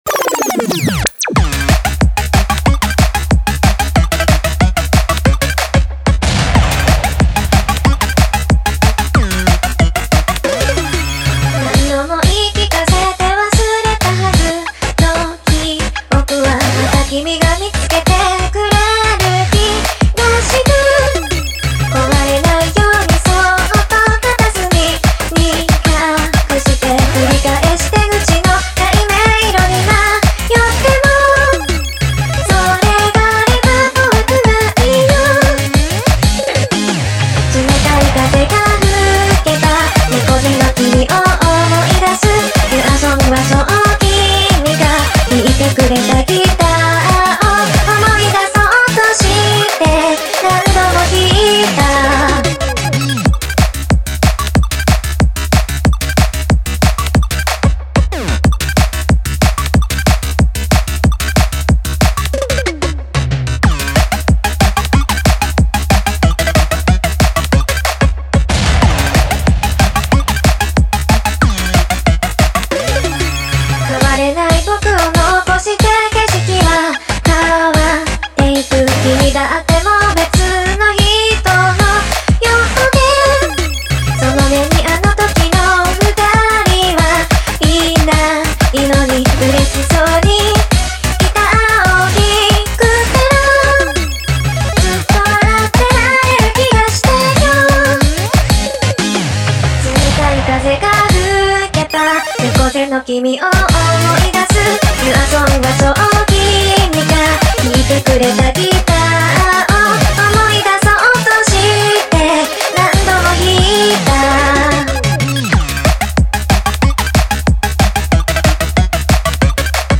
歌、音ゲー、コミカル、かっこいい、楽しい、アップテンポ、ノリノリEDM